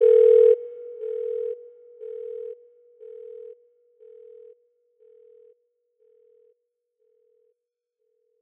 56 Nights Phone FX.wav